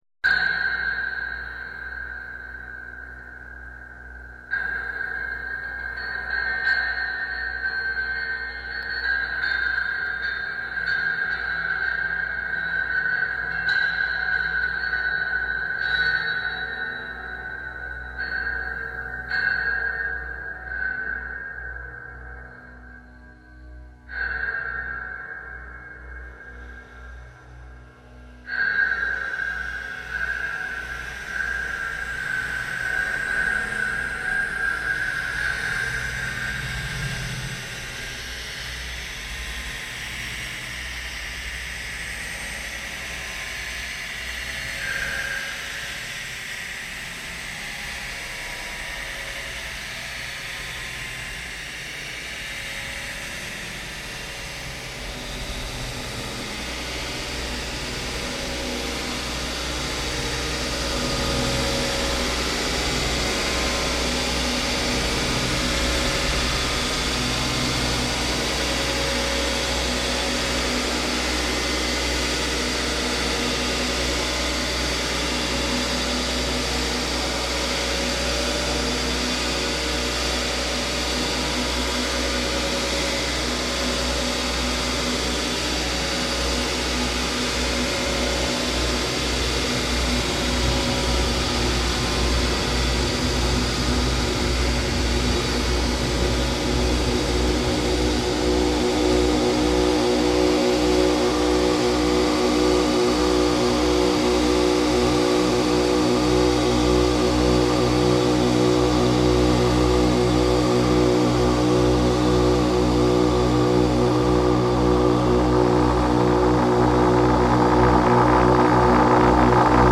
His inflections are almost musical.